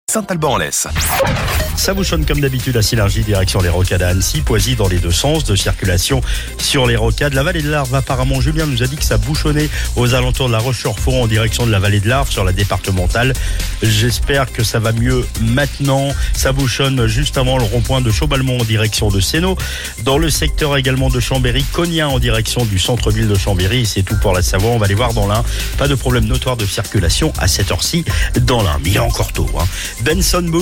Info trafic